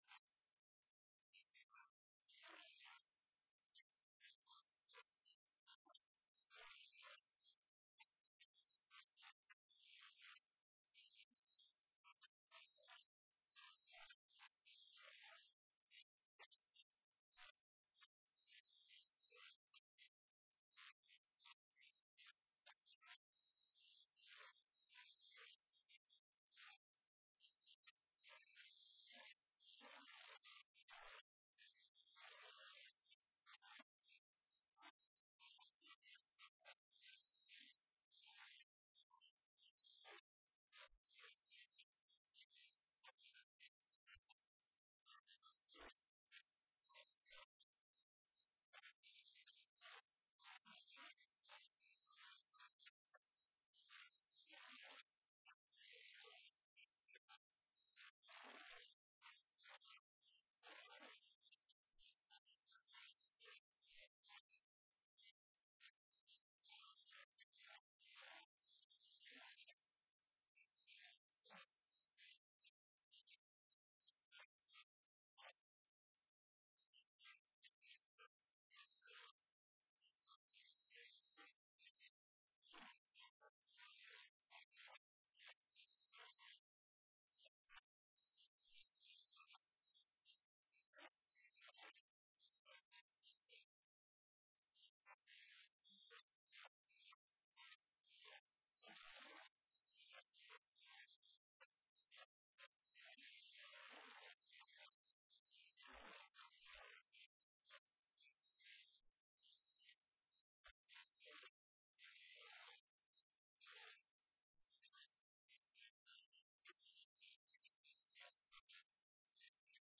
In the 2000s EMOHA added short oral history clips from the Archive to village pages on the Leicestershire Villages website.